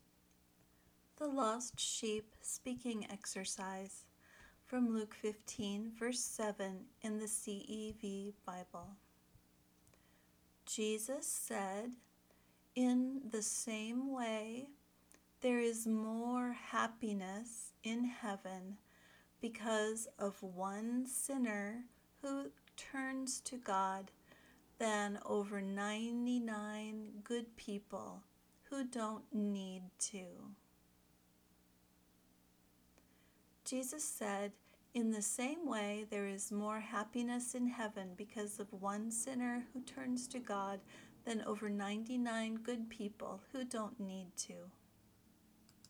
1. First, listen to the recording (in slow English) one time without speaking. At the end, she will say the verse at normal speaking speed for you to repeat if you like.